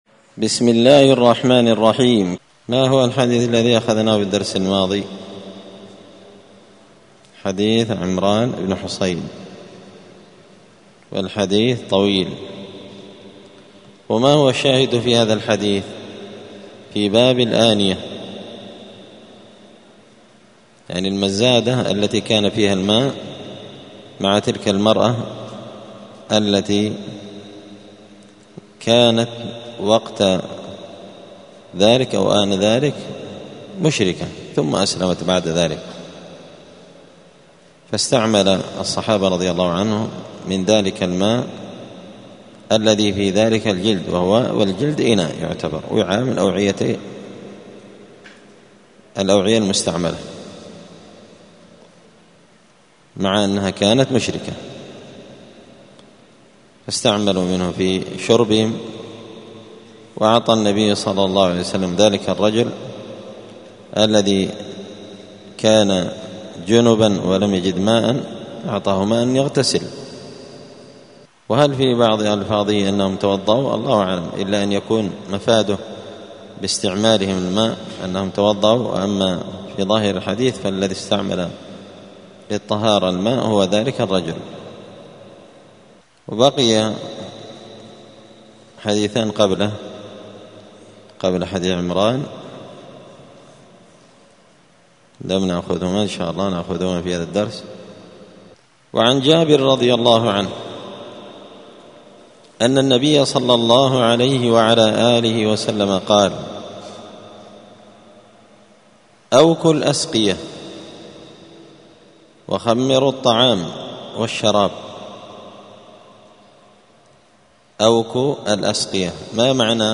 دار الحديث السلفية بمسجد الفرقان بقشن المهرة اليمن
*الدرس الثاني عشر (12) {باب الآنية حكم استخدام جلد الحيوان المأكول اللحم…}*